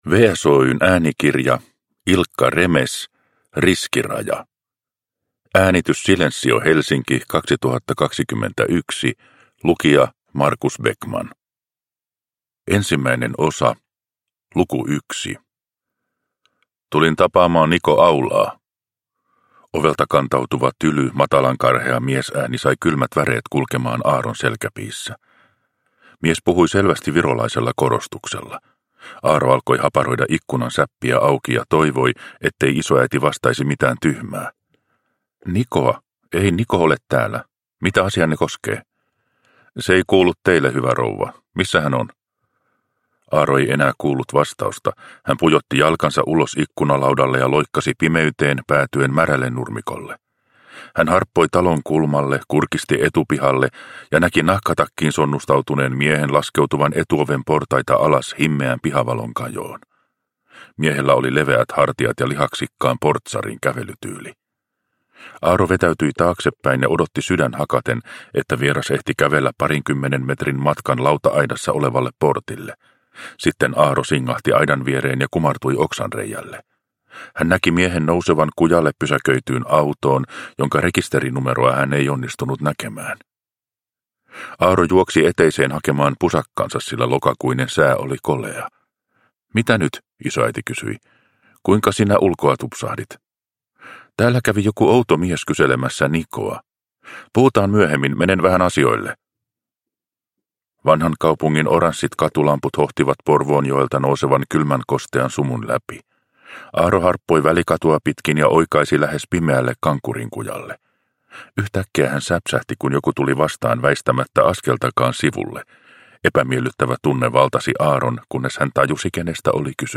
Riskiraja – Ljudbok – Laddas ner